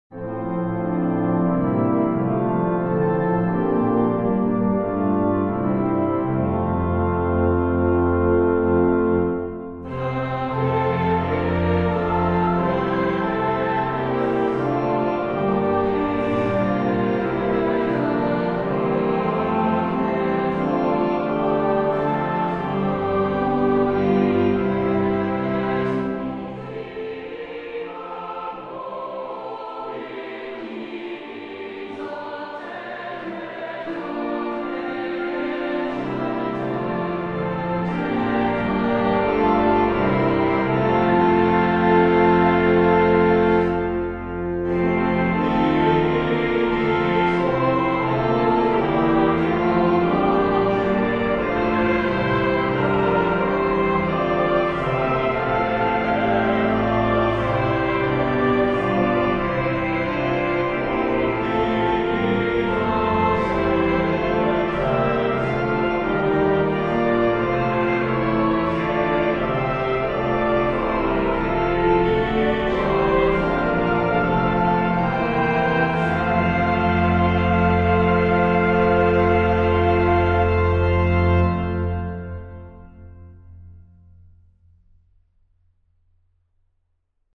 recorded in 2006 at Church of the Advent, Boston, sung by the Choir of Christ Church (Hamilton Mass.)
organist
Audio: hymnal verse / descant verse (with the lower ending ).